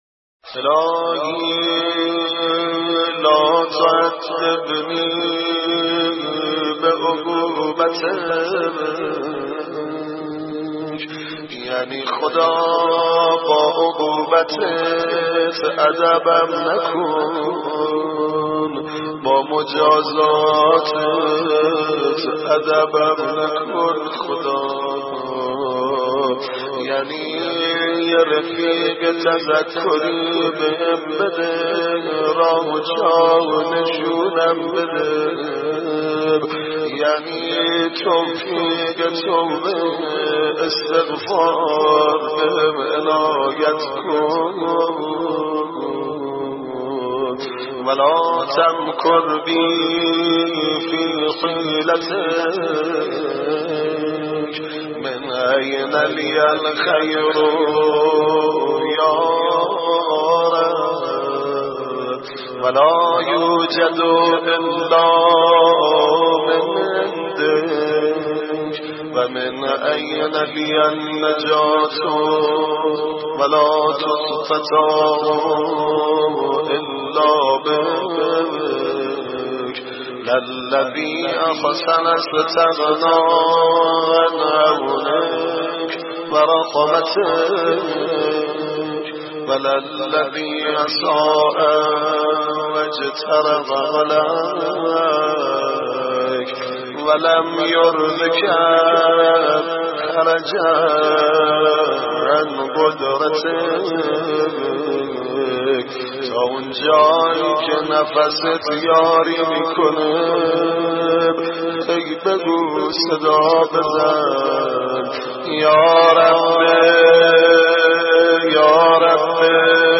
دعا.mp3